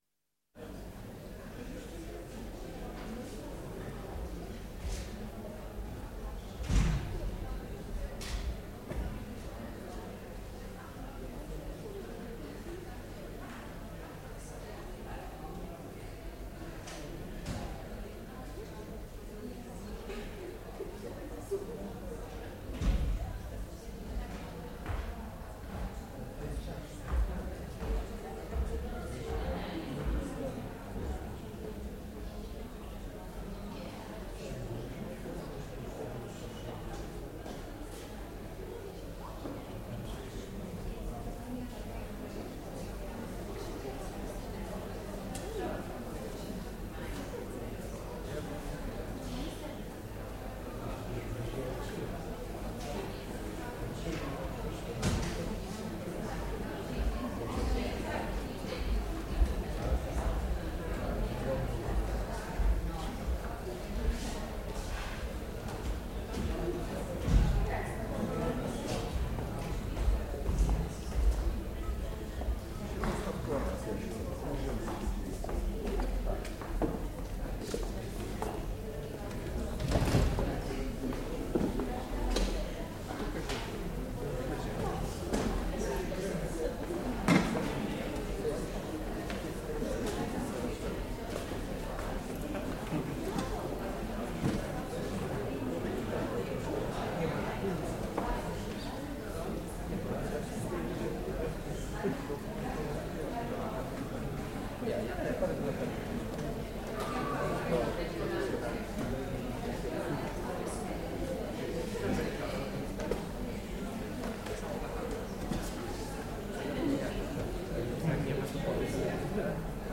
Здесь вы найдете фоновые шумы разговоров, звон бокалов, смех гостей и другие характерные звуки заведения.
Атмосферные звуки бара: народ собирается внутри, пока еще начало вечера